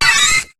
Cri de Pichu dans Pokémon HOME.